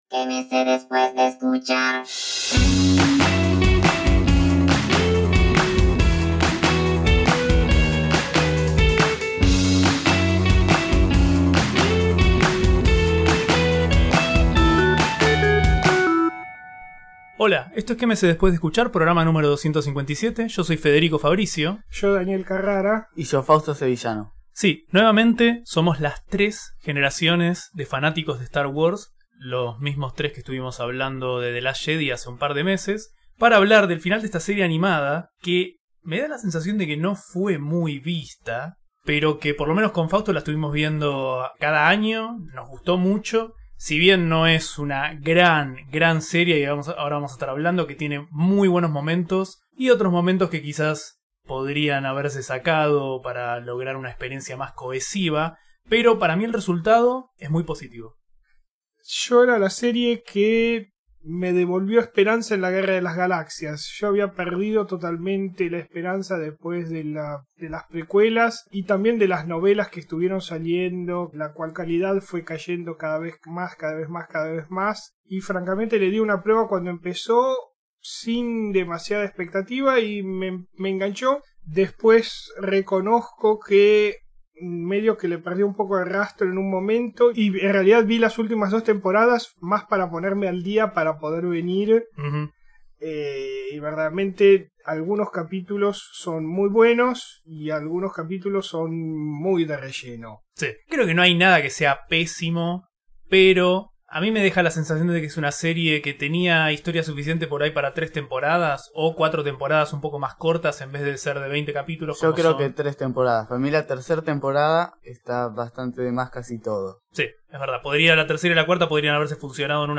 En este podcast, miembros de tres generaciones diferentes de fanáticos de Star Wars hablan sobre el final de Star Wars Rebels, la segunda serie animada de Dave Filoni que sucede en una galaxia muy, muy lejana.